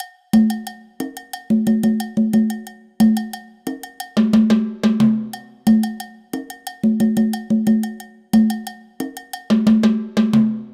Cumbia.wav
Folclor colombiano, Región Caribe, loops, bases rítmicas, banco de sonidos
Folclor de Colombia, Región Caribe, ritmo, instrumentos de percusión